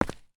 VoxelEngine / res / content / base / sounds / steps / stone_1.ogg
stone_1.ogg